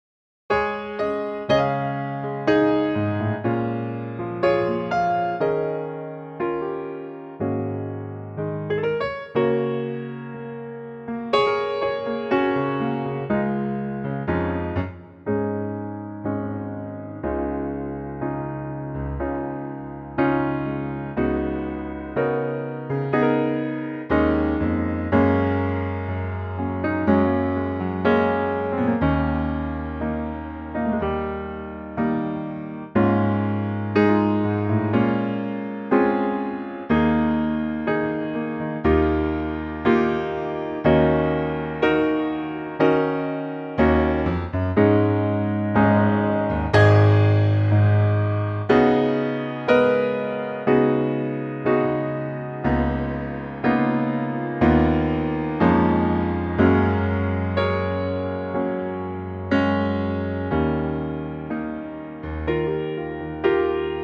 Unique Backing Tracks
key - Db - vocal range - F to Ab (optional Bb)